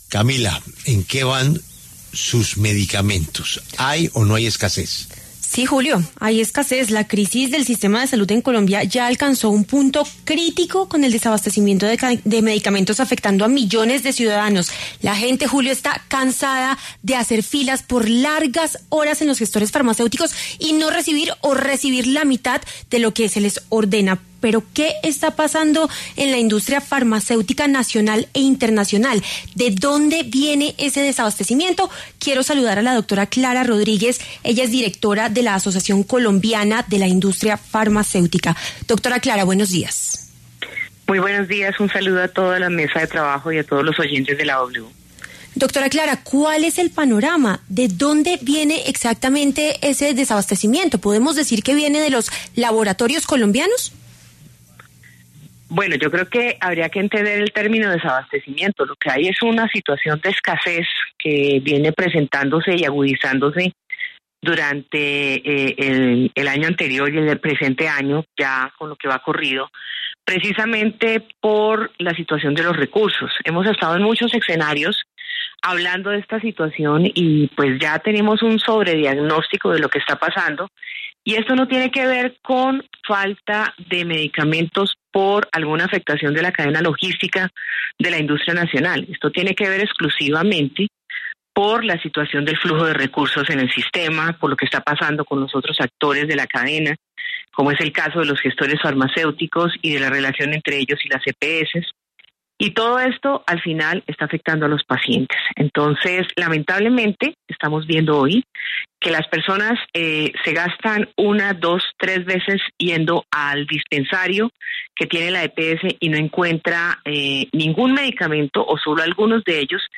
Las EPS enfrentan una deuda millonaria con los gestores farmacéuticos, quienes a su vez deben pagar a los laboratorios productores de los medicamentos. En La W hablamos con los laboratorios nacionales e internacionales.